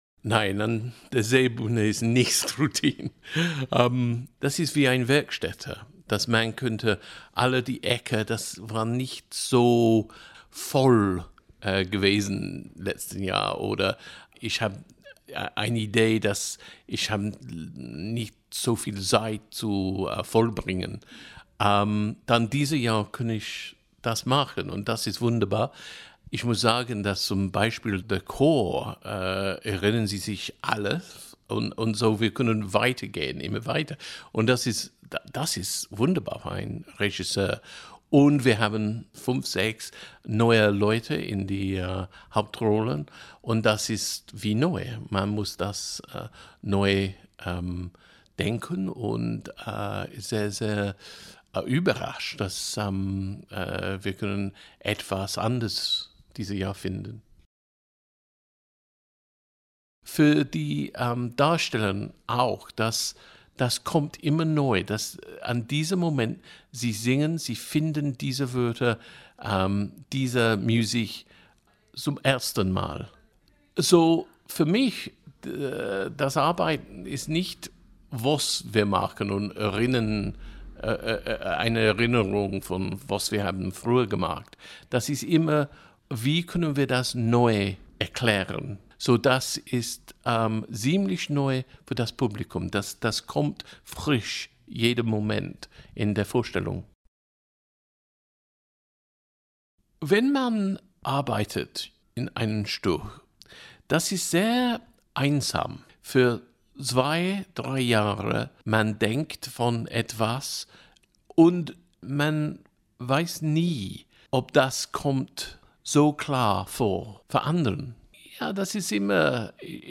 Interview mit Keith Warner, Regisseur bei André Chénier